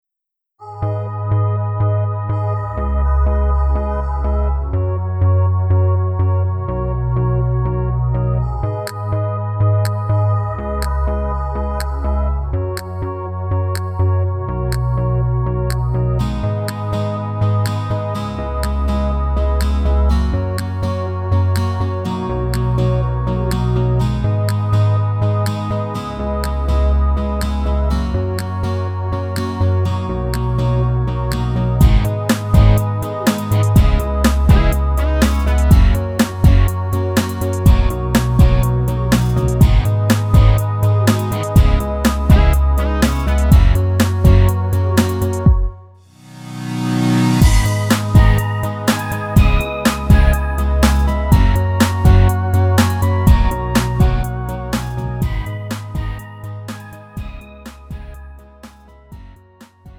장르 가요
Lite MR